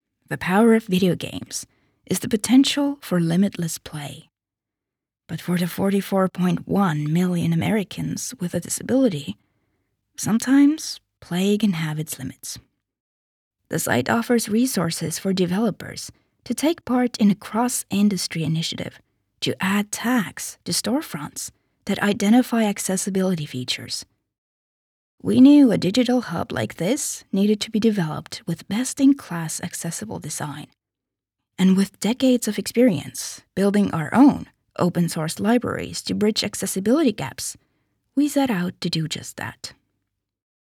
Narración
Soy locutora estonia nativa y trabajo tanto en estonio como en inglés, ¡con un ligero acento!
Mi acento es lo suficientemente suave como para ser fácilmente comprensible, además de ser adecuado para conectar con gente de todo el mundo.